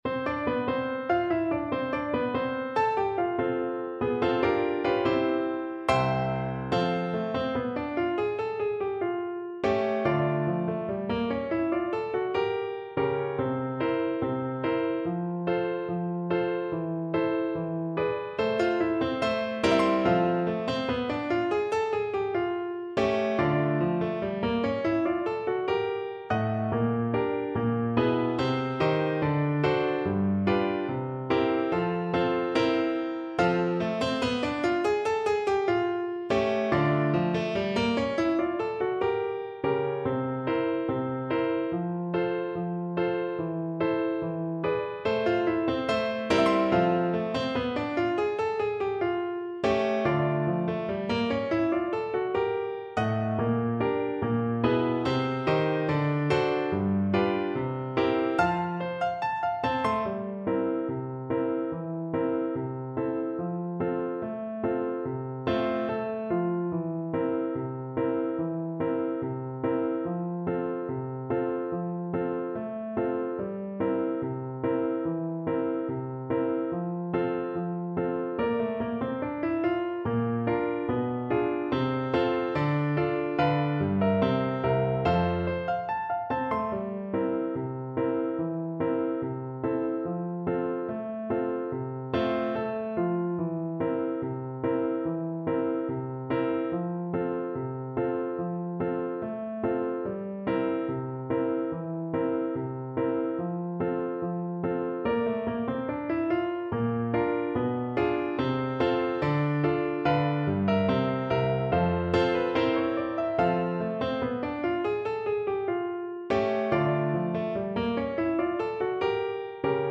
F major (Sounding Pitch) (View more F major Music for Oboe )
2/4 (View more 2/4 Music)
Slow march tempo = 72
Jazz (View more Jazz Oboe Music)